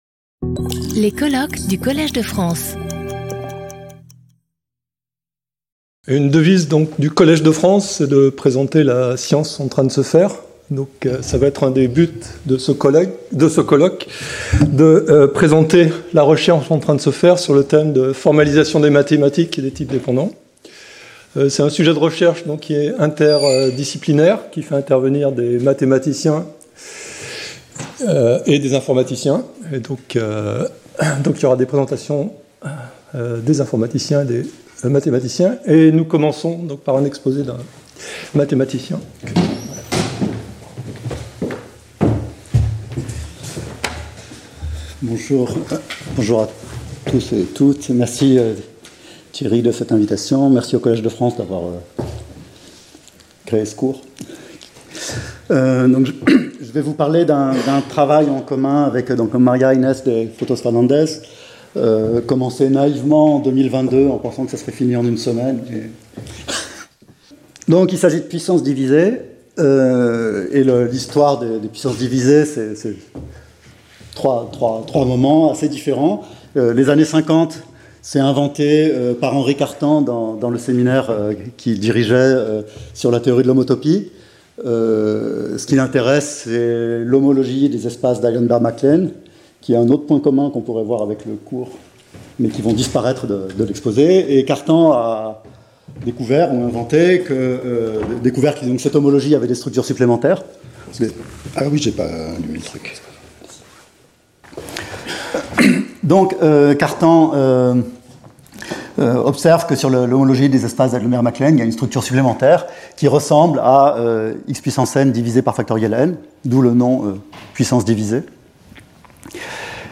Colloque